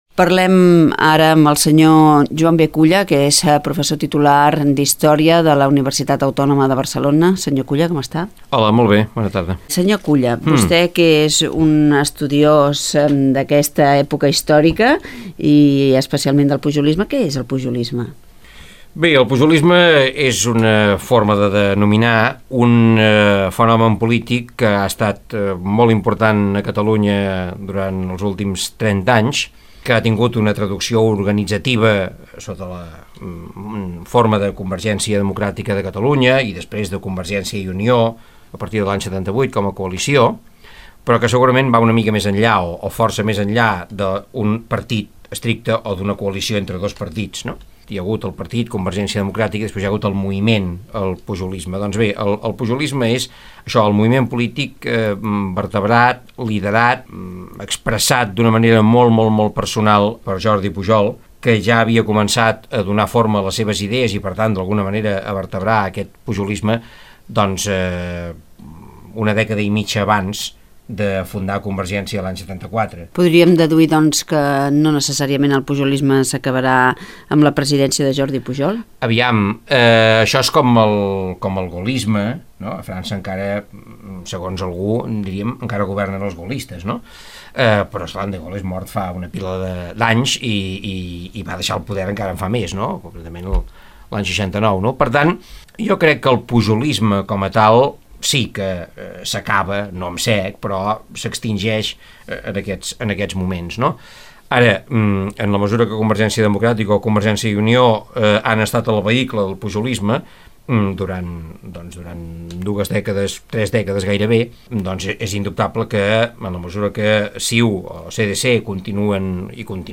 Reportatge: El Pujolisme (Joan B. Culla i Antoni Gutiérrez Díaz) - Ràdio 4, 2003